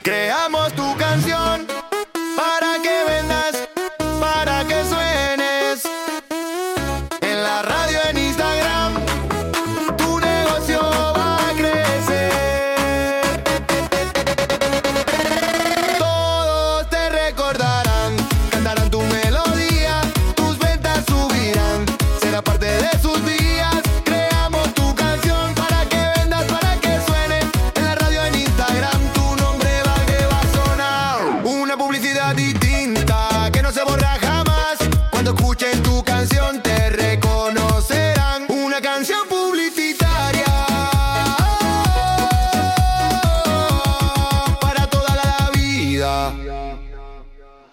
Reggaetón moderno
Reggaetón discoteca – Voz masculina